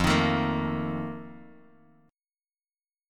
F11 Chord
Listen to F11 strummed